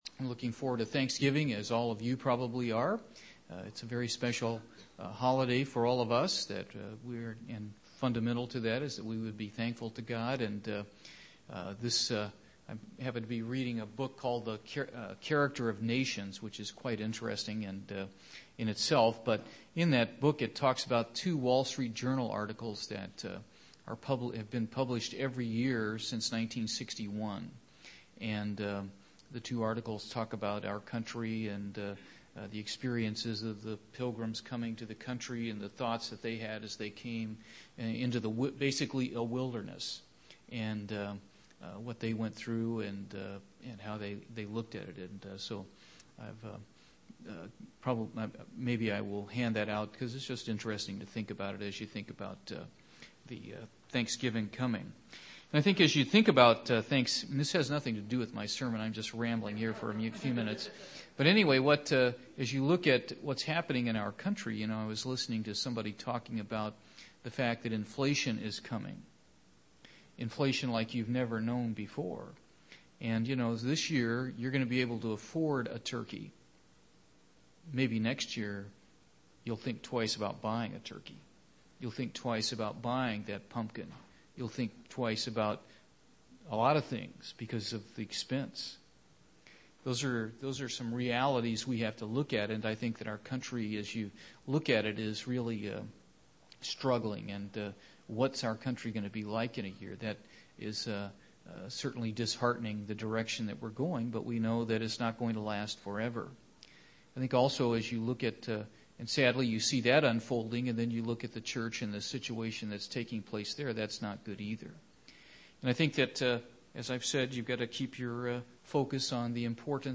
Given in Portsmouth, OH
UCG Sermon Studying the bible?